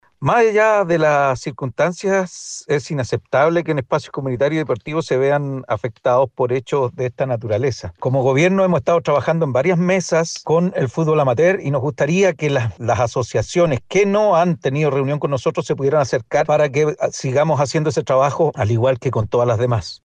Por su parte, el Delegado Presidencial Provincial de Marga Marga, Fidel Cueto, declaró que han trabajado en varias mesas con el fútbol amateur, por lo que hizo un llamado a las asociaciones que no han sostenido reuniones para que se unan en pos de esta problemática.